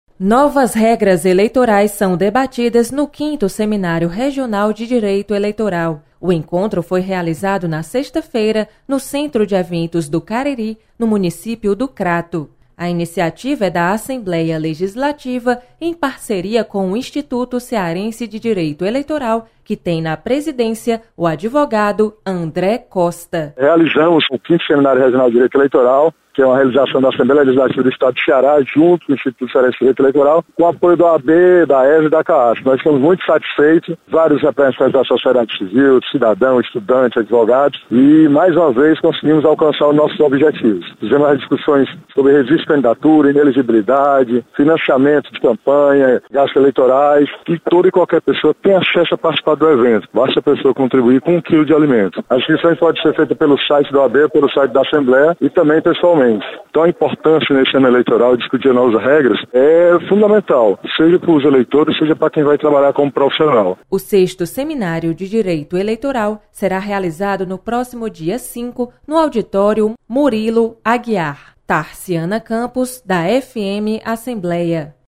Novas regras eleitorais são debatidas em seminário. Repórter